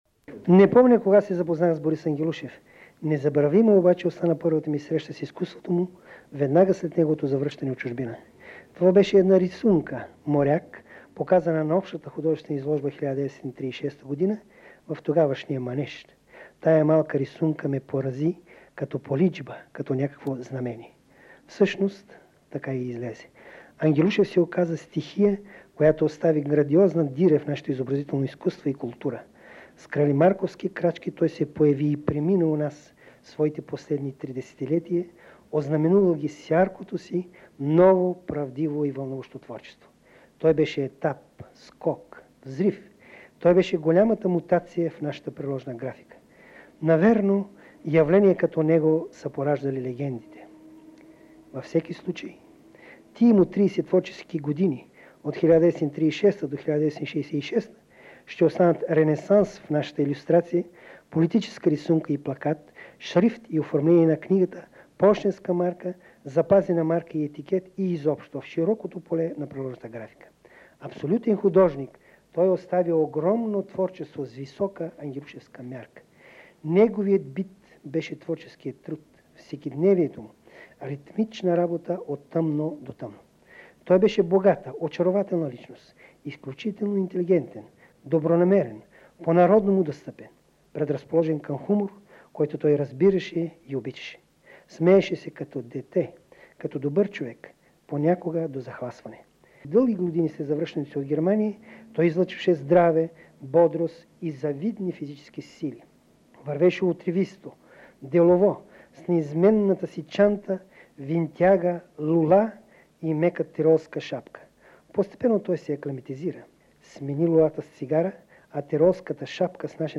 запис 1976 година, Златен фонд на БНР: